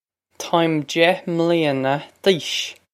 Pronunciation for how to say
Taw-im deh mlee-un-ah d-eesh.
This is an approximate phonetic pronunciation of the phrase.